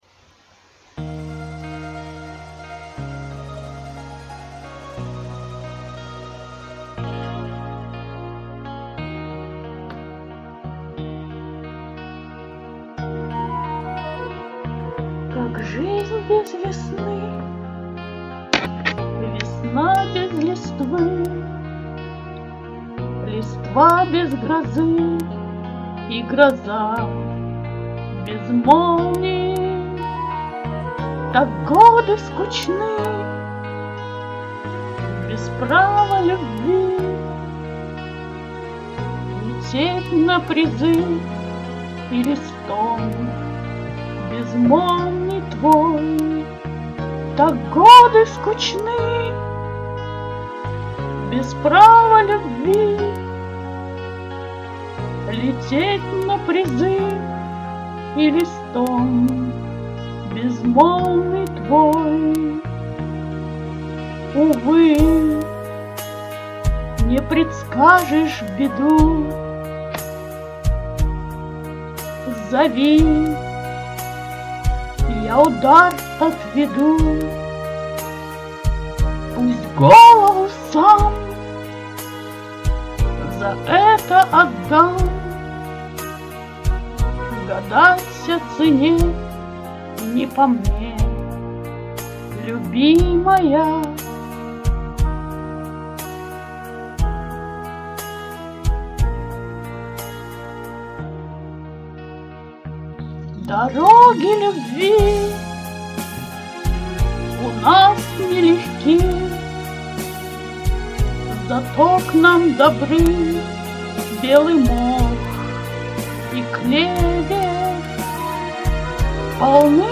Кавер-версия Из кинофильма